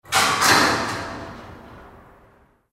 Puerta de un parking